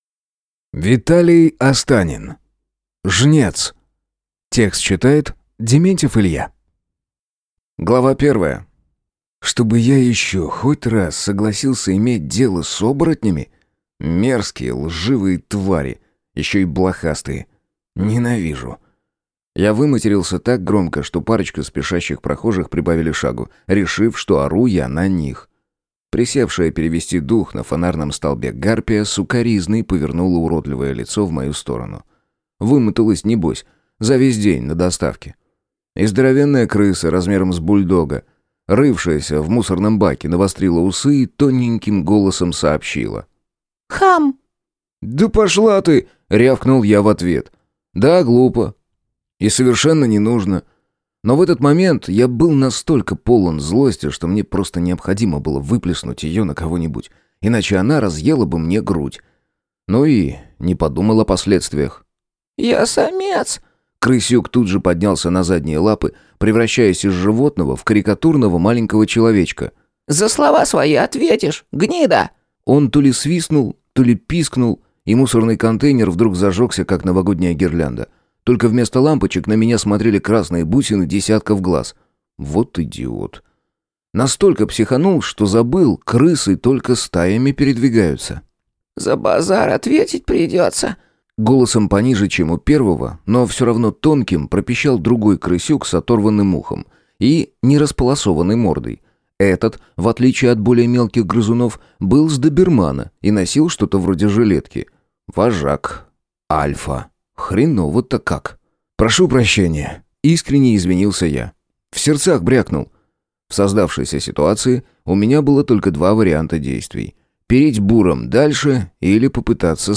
Аудиокнига Жнец | Библиотека аудиокниг